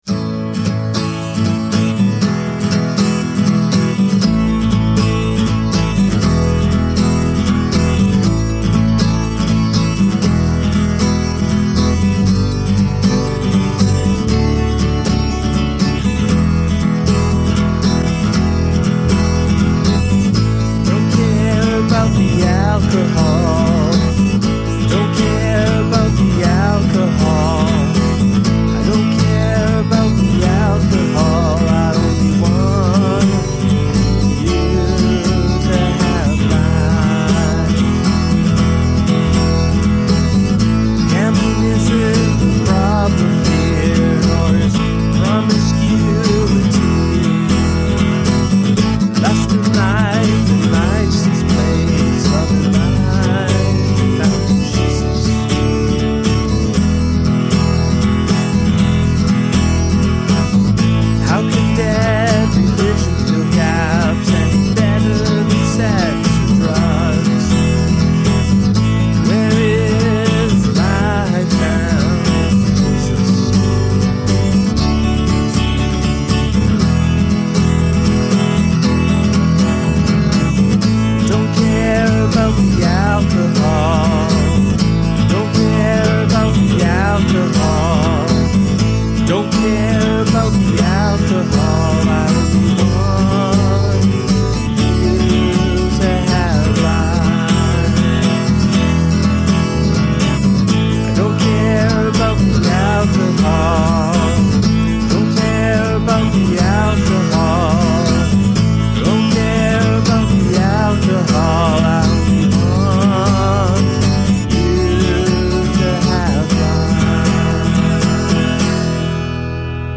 It’s fairly angry, written about some friend and/or family problems that I believe are at least mostly behind us (this was recorded more than 10 years ago).
I play bass and guitar, and sing.